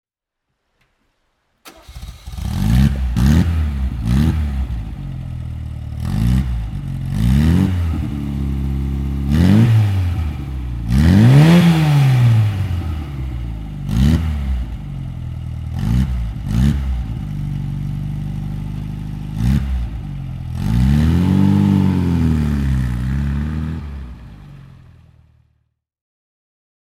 Abarth Scorpione (1970) - Starten und Leerlauf
Abarth_Scorpione_1970.mp3